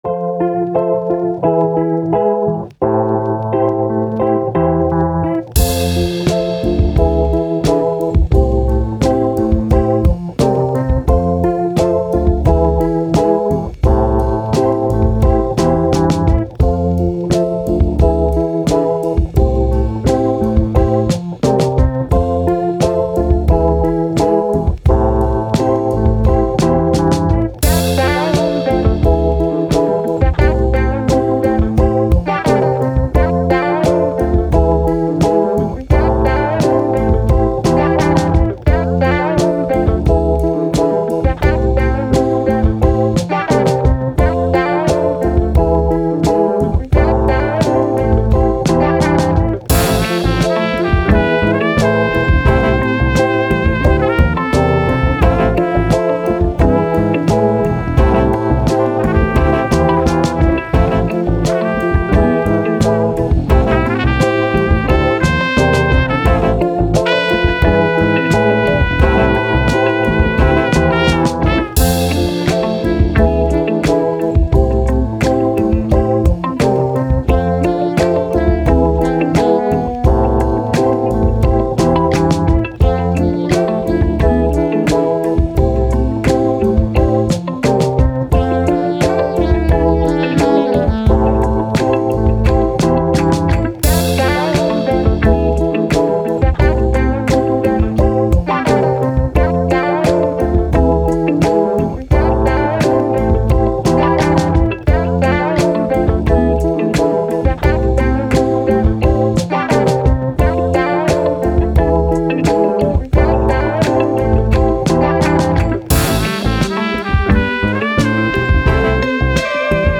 Soul. Hip Hop, Chill, Positive